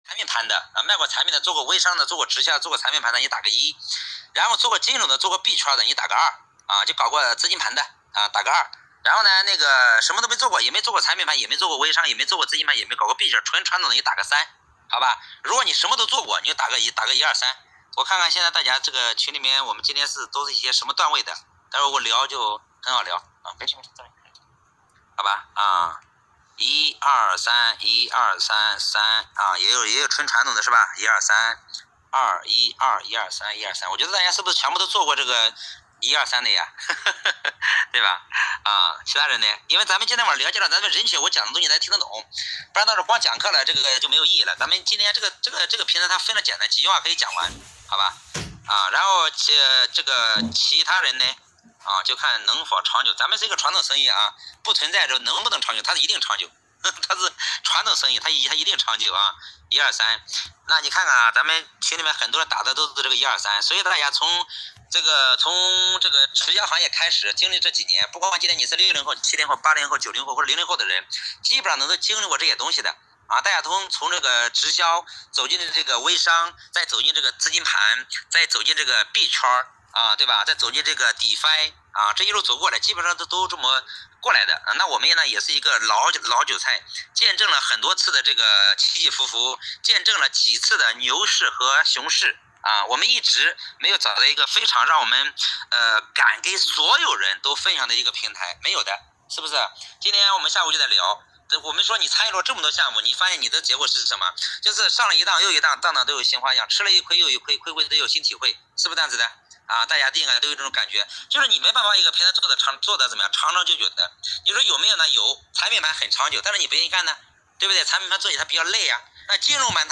4.4晚 淘嘻嘻价值远景和规划语音课